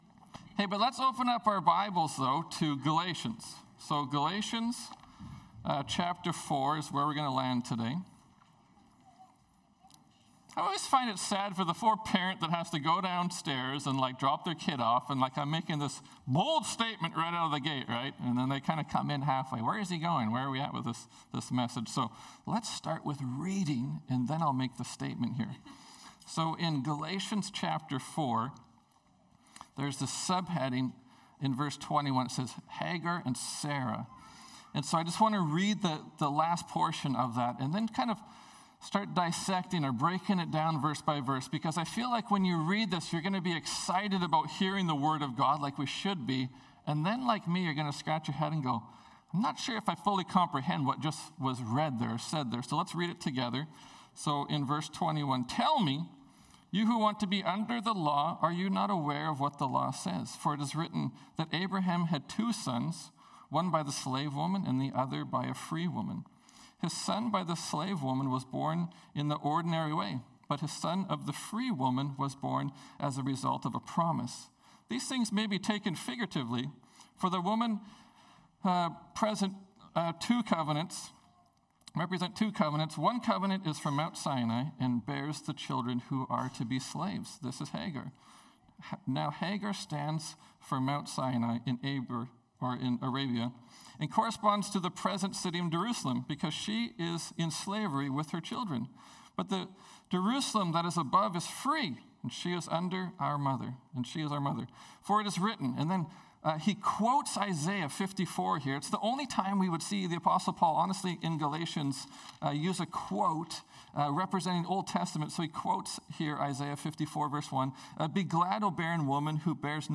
Sermons | Terrace Pentecostal Assembly